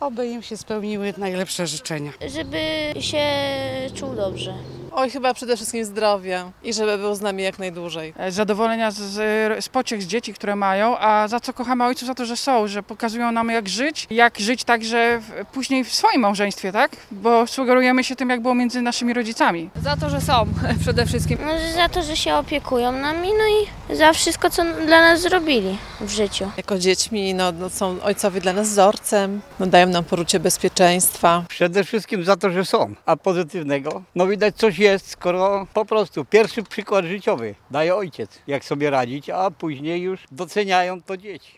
Dzień Ojca [SONDA]
Zielonogórzanie składają życzenia wszystkim ojcom.
A za co zielonogórzanie cenią najbardziej swoich ojców? W rozmowach z nami mieszkańcy podkreślali, że przede wszystkim za to, że po prostu są: